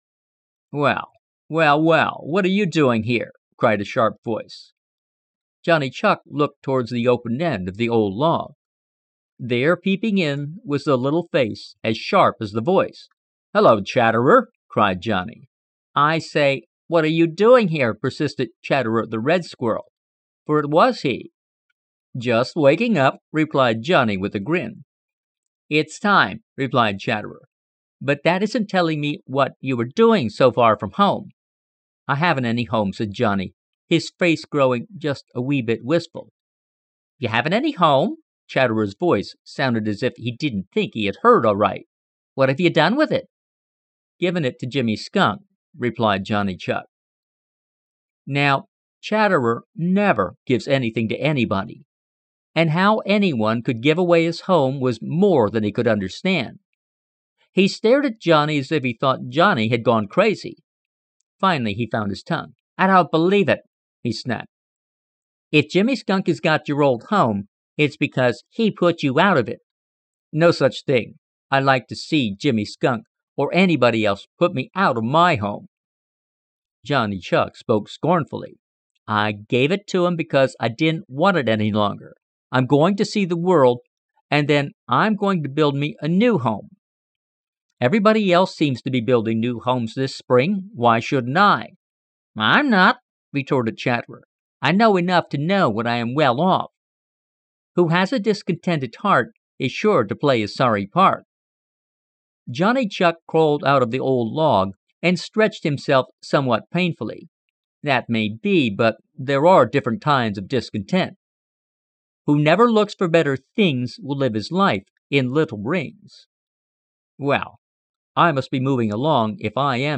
Children's and adult audiobooks